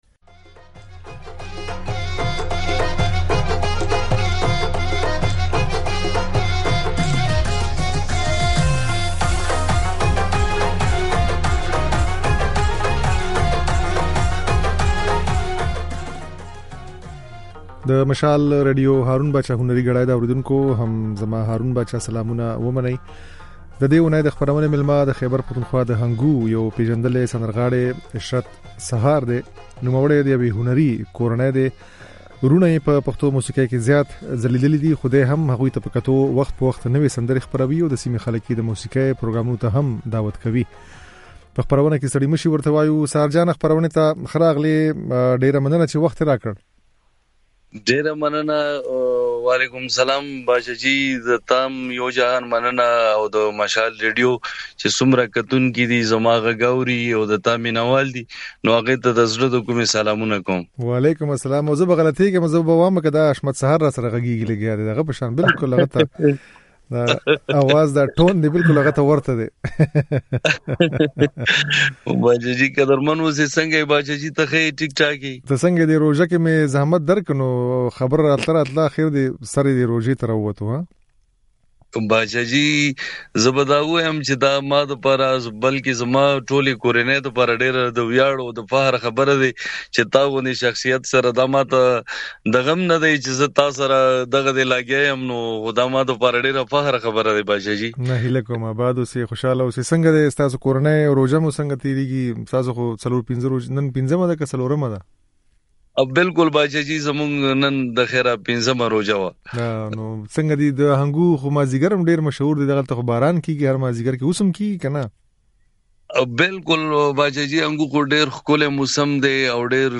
د نوموړي دا خبرې او ځينې سندرې يې په خپرونه کې اورېدای شئ.